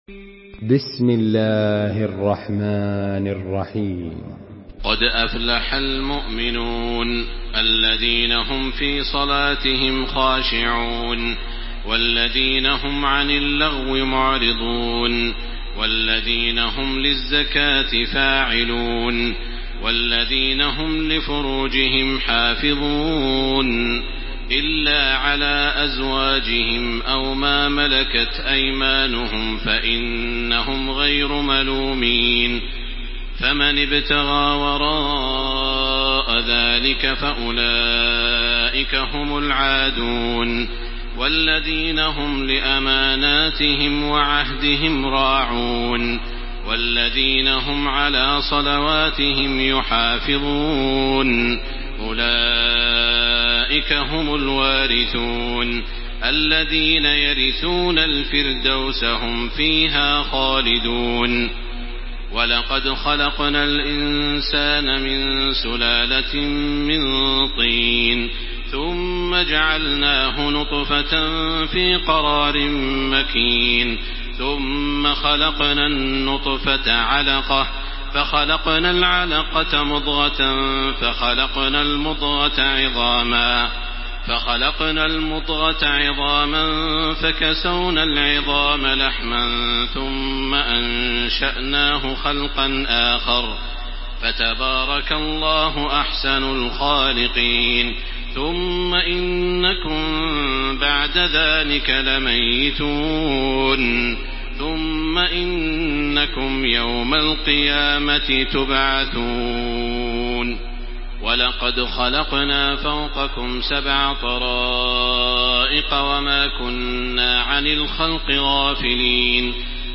تحميل سورة المؤمنون بصوت تراويح الحرم المكي 1434
مرتل